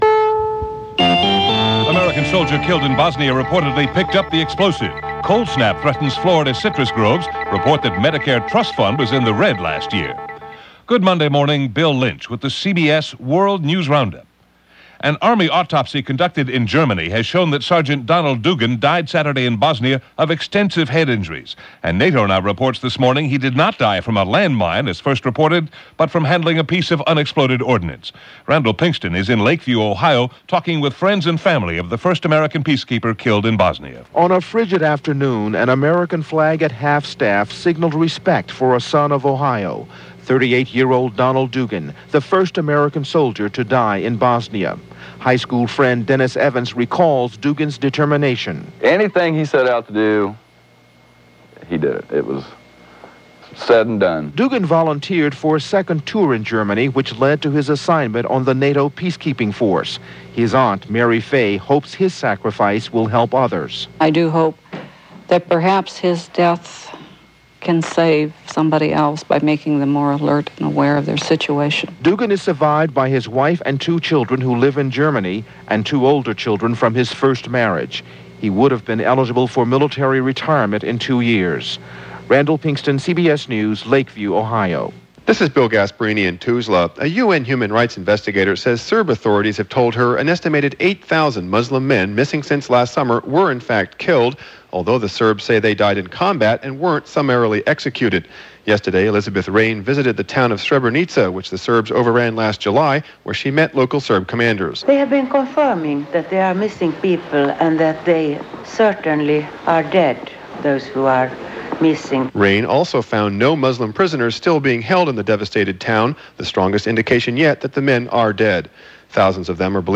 And that’s just a little of what was going on, this February 5, 1996 as reported by The CBS World News Roundup.